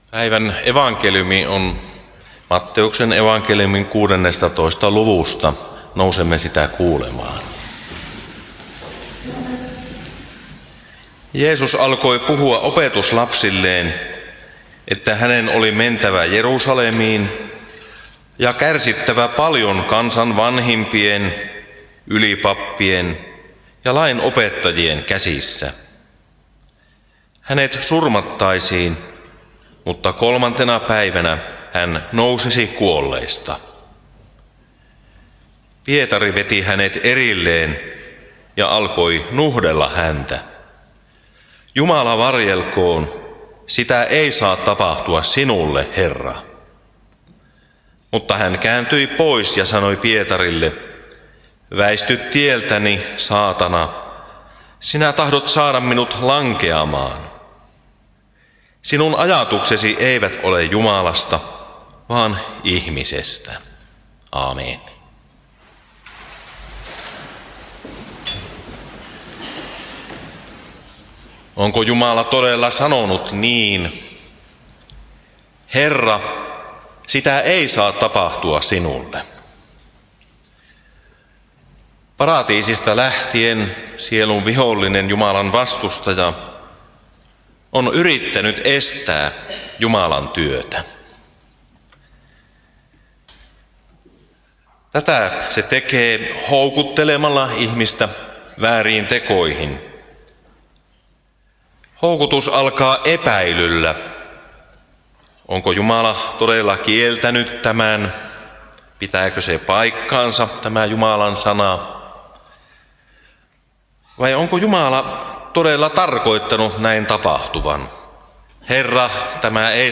Alajärvellä 1. paastonajan sunnuntaina Tekstinä Matt. 16:21–23